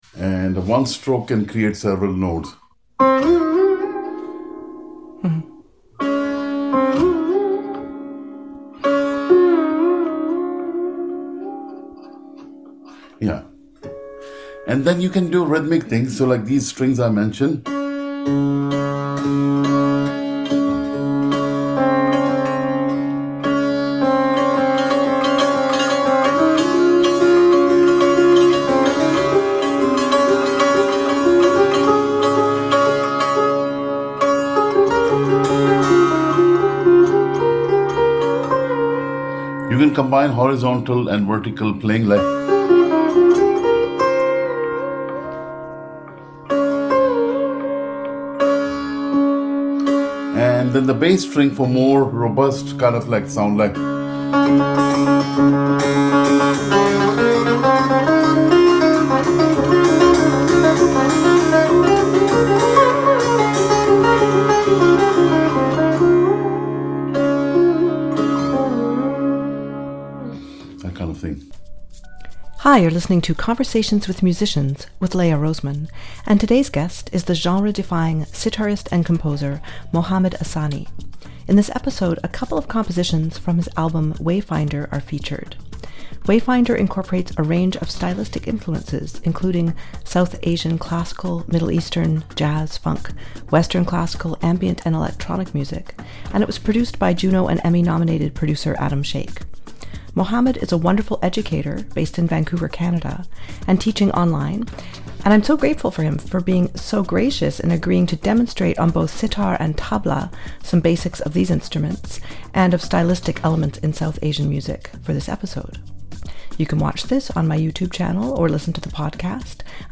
(25:28) tabla demonstration